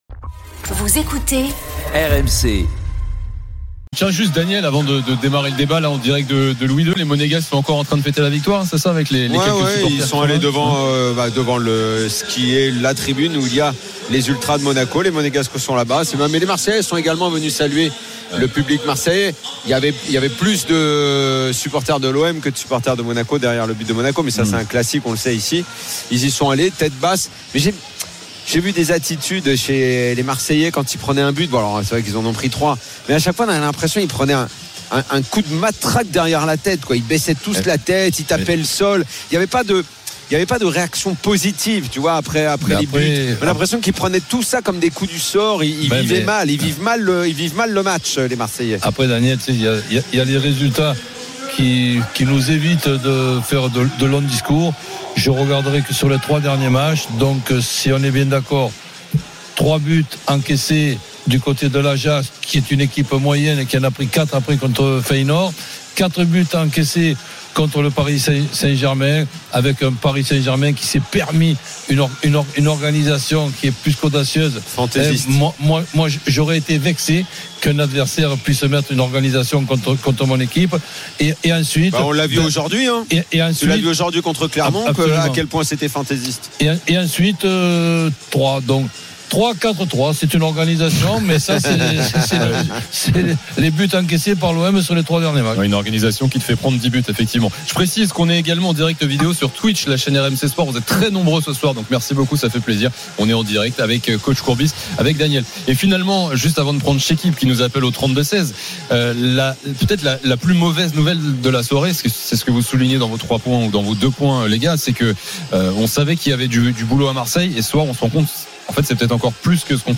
Chaque jour, écoutez le Best-of de l'Afterfoot, sur RMC la radio du Sport !
L’After foot, c’est LE show d’après-match et surtout la référence des fans de football depuis 15 ans !
RMC est une radio généraliste, essentiellement axée sur l'actualité et sur l'interactivité avec les auditeurs, dans un format 100% parlé, inédit en France.